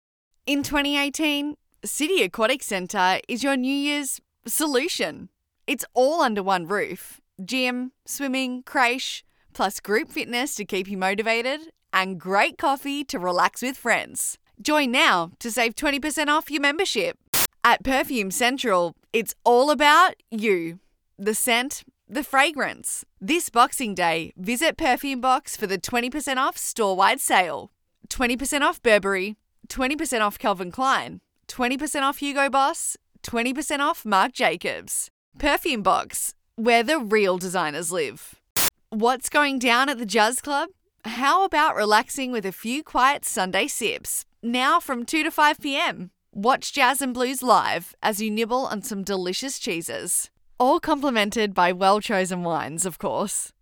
• Hip Young Cool
• Natural